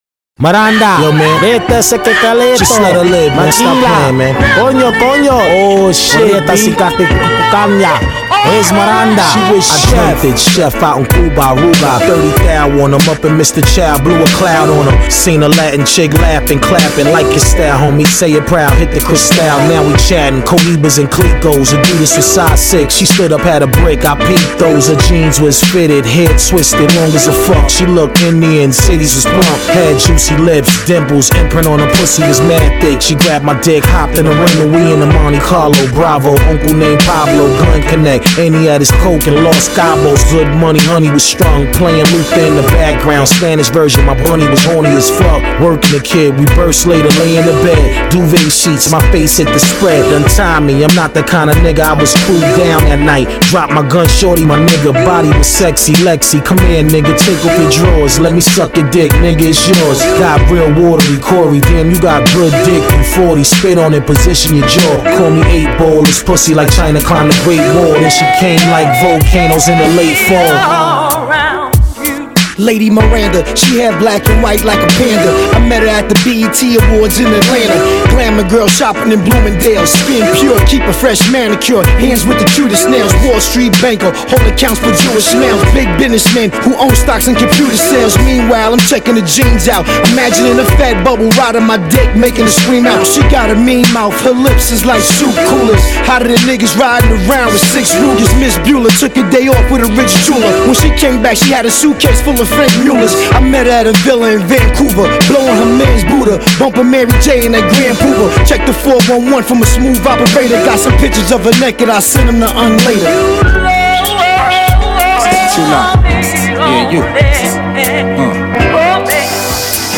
This is dope, str8 retro hip hop.